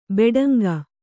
♪ beḍanga